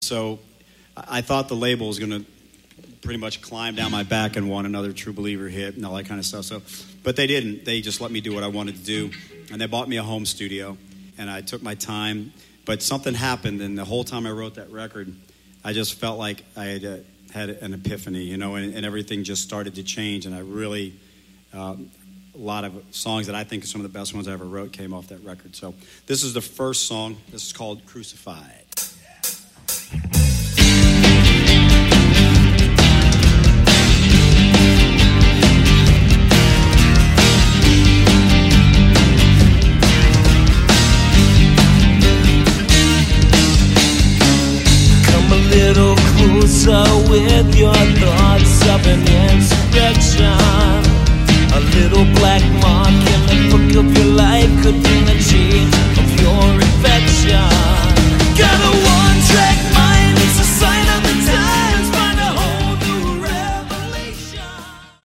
Category: Hard Rock
lead vocals
bass
drums
acoustic guitar, vocals